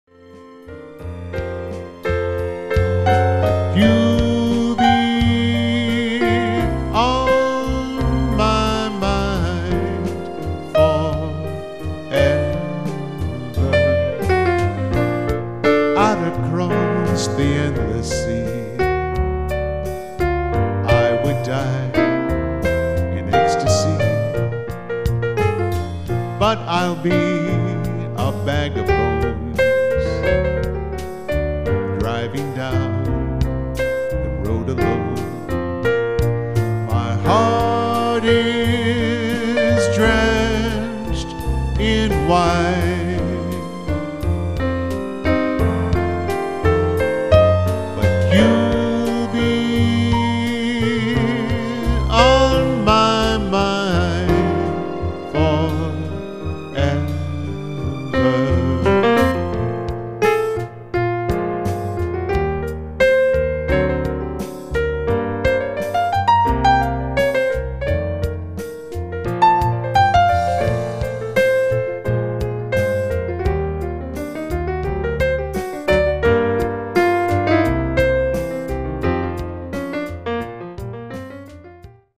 band/vocal